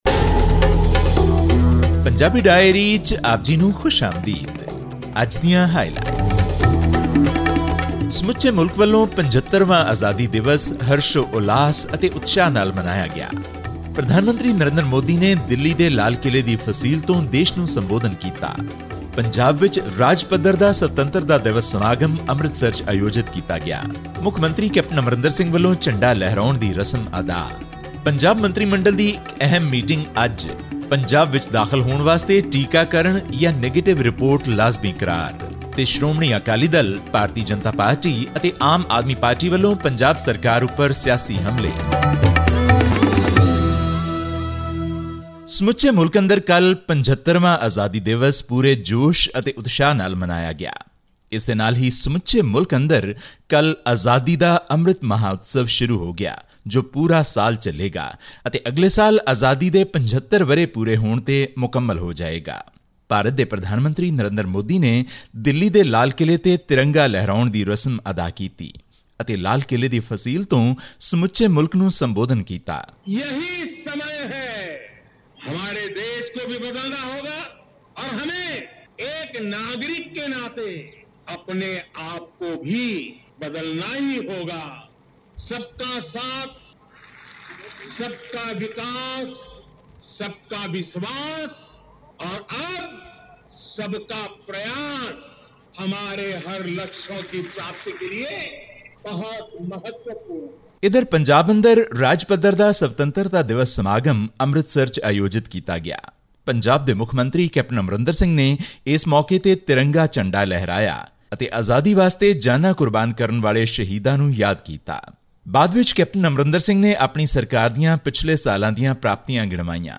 Amid reports of another surge in COVID infections, the Punjab government has made a negative RT-PCR test report or a full vaccination certificate mandatory to enter the state. All this and more in our weekly news bulletin from Punjab.